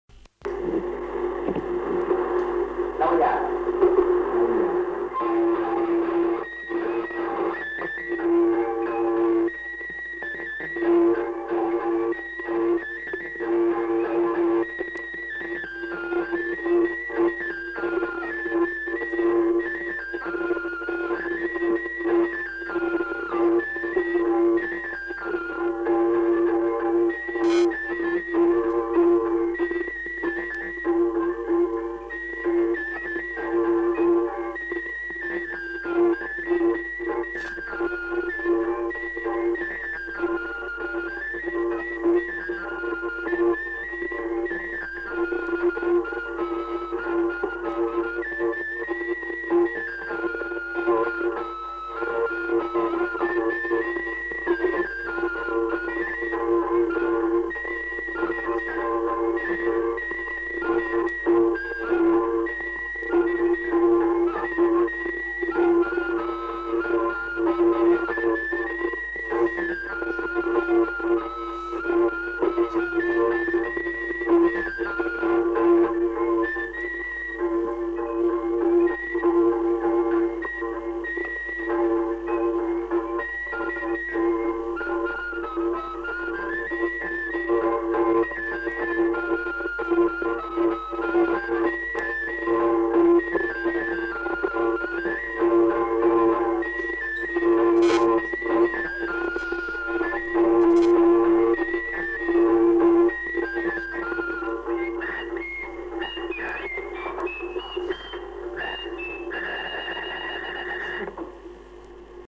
Aire culturelle : Béarn
Lieu : Bielle
Genre : morceau instrumental
Instrument de musique : flûte à trois trous ; tambourin à cordes
Danse : branlo airejan